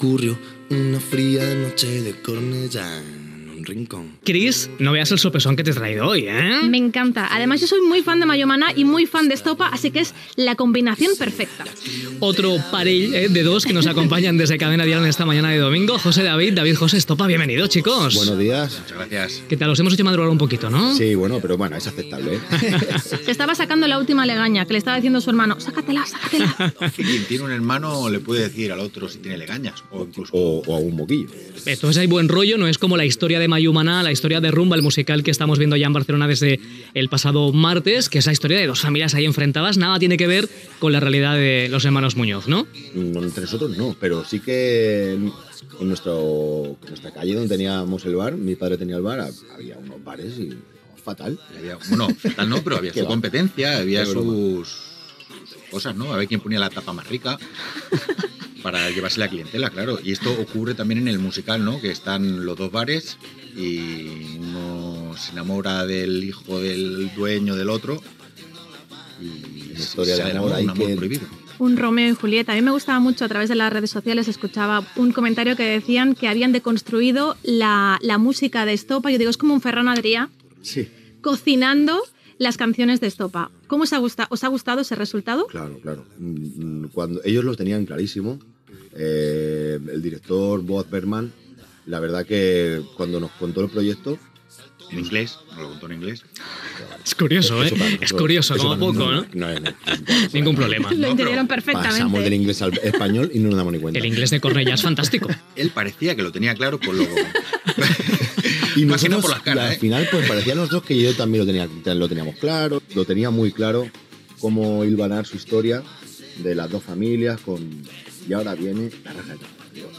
Entrevista a David Muñoz i José Manuel Muñoz, Estopa, que presenten l'espectacle "Rumba" del grup Mayumaná al teatre Victoria de Barcelona